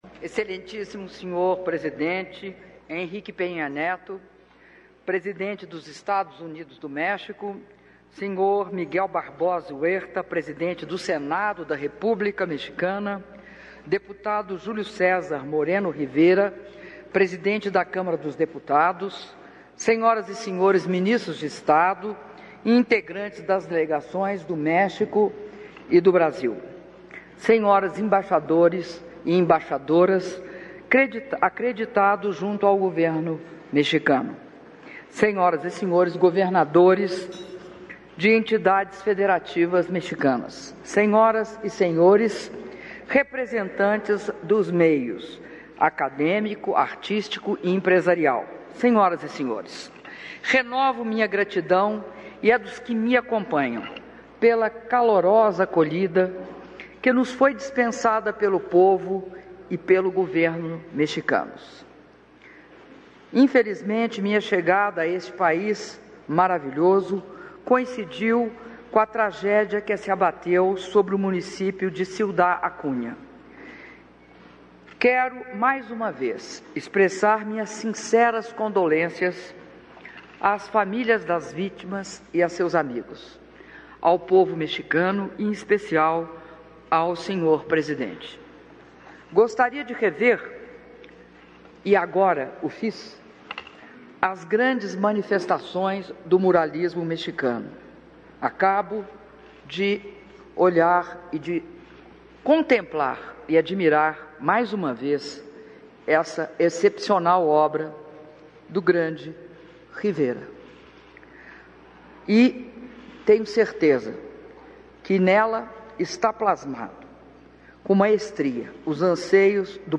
Áudio do brinde da presidenta da República, Dilma Rousseff, durante almoço oferecido pelo presidente do México, Enrique Peña Nieto (10min21s) - Cidade do México-México